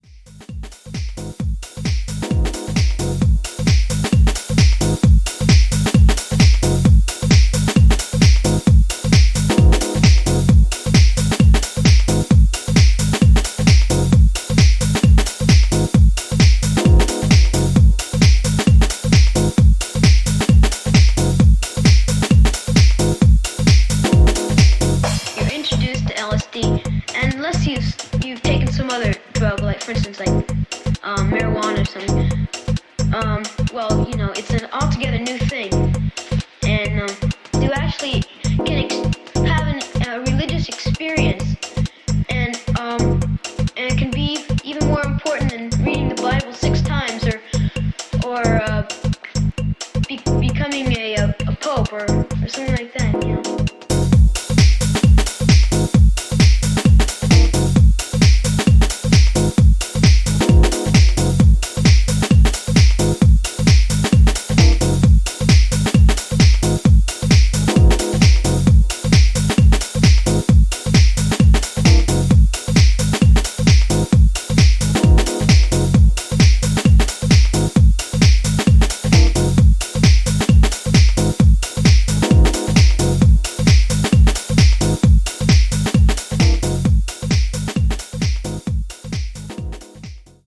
ここでは、モダンでバウンシーなプログレッシヴ・ハウス路線の4曲を展開。ジャズキーを配しミニマリーに疾走する